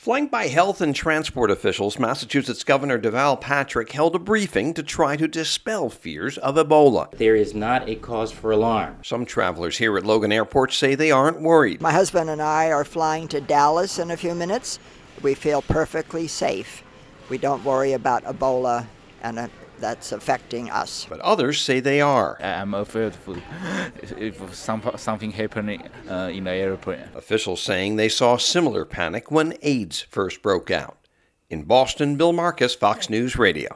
Gov Deval Patrick at Logan briefing to dispel ebola fears
THIS WOMAN TRAVELING THROUGH LOGAN AIRPORT SAYS SHES CONCERNED ABOUT EBOLA BUT CONFIDENT – SOMEWHAT – THAT HOSPITALS ARE TAKING CARE OF IT.